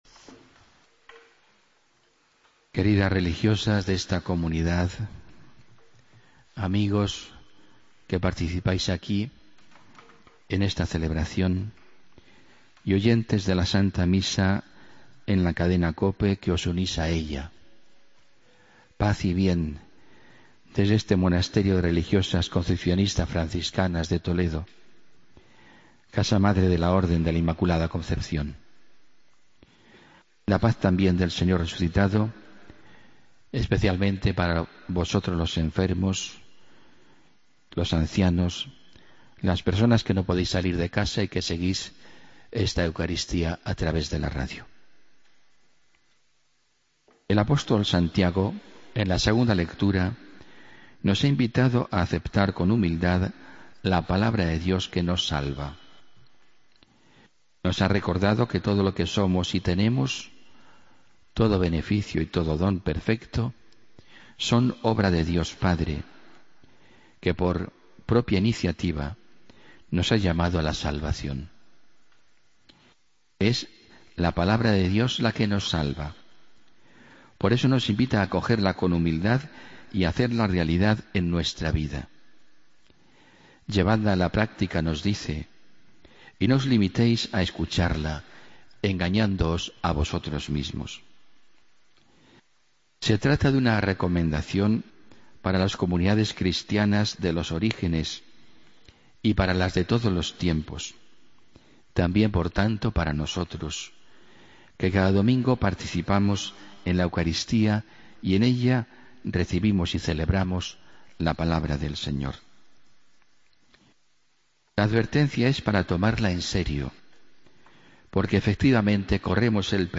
Homilía del domingo302 de agosto de 2015